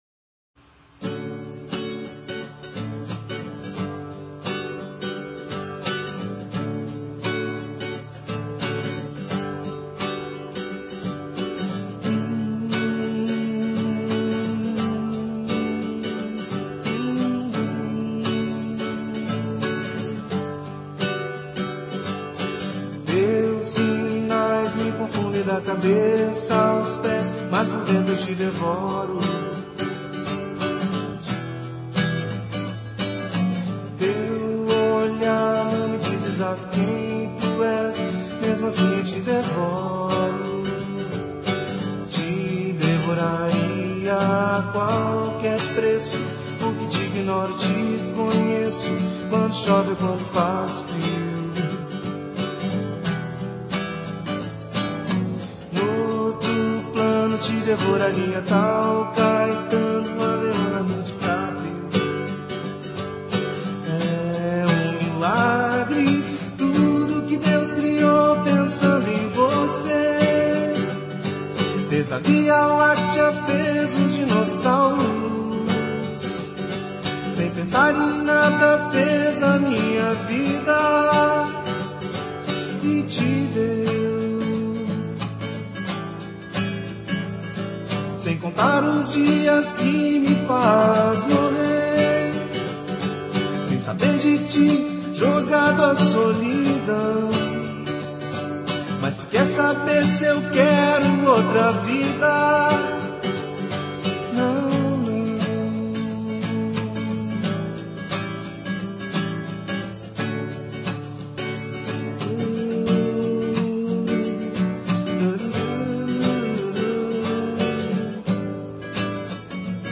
MPB